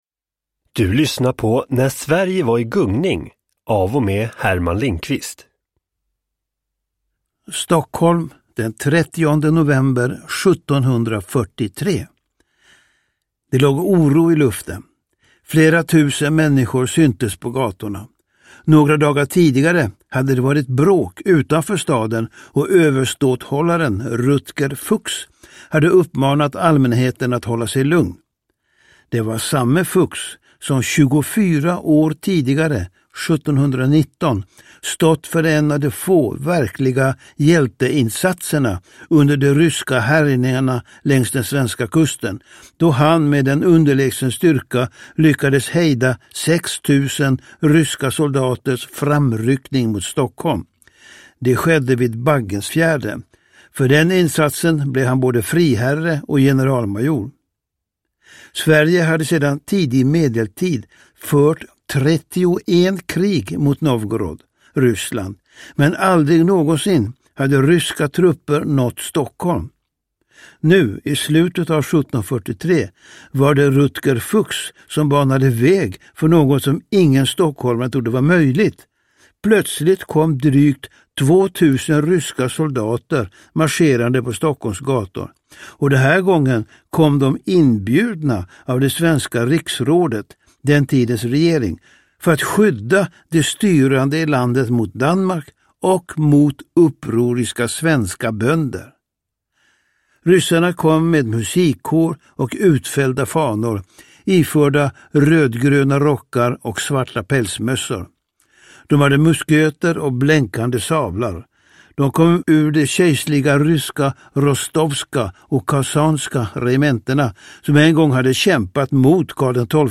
Nedladdningsbar Ljudbok
Berättare
Herman Lindqvist